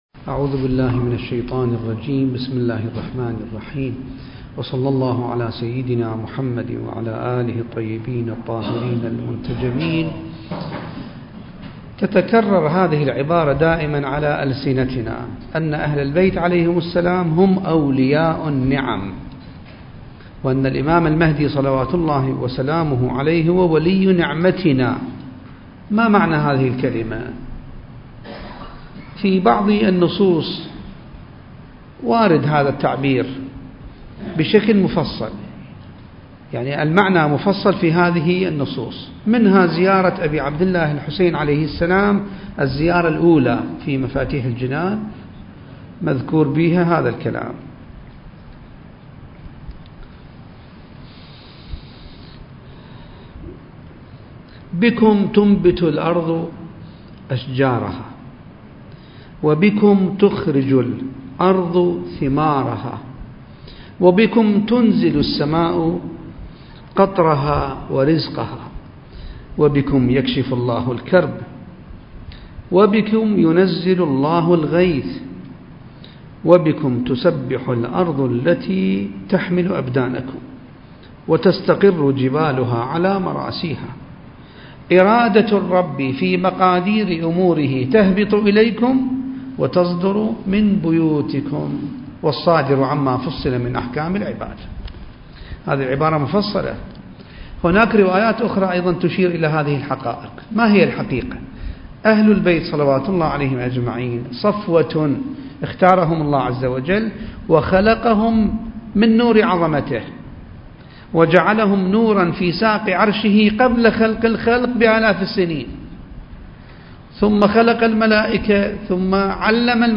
المكان: جامع الصاحب (عجّل الله فرجه) - النجف الأشرف